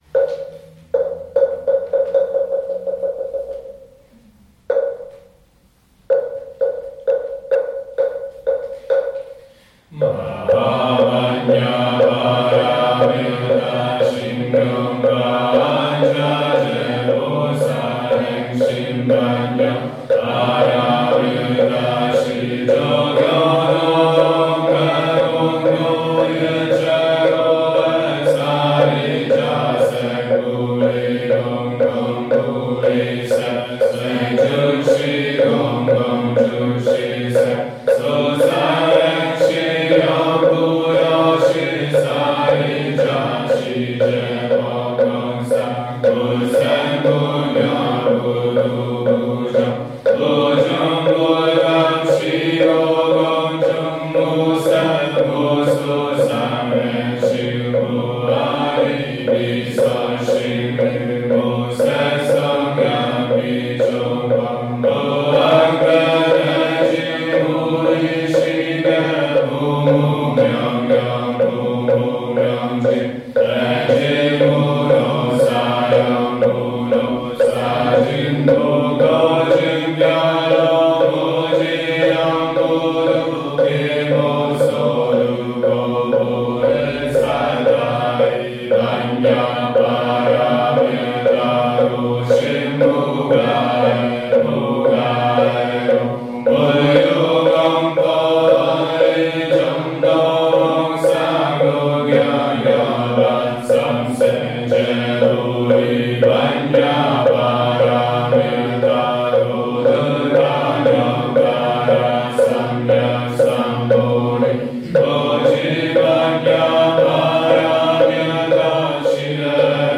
Letöltések - Meditációs énekek
Az alábbi linkekkel letöltheted énekeskönyvünket és a rendszeres gyakorlatunk során recitált énekeket: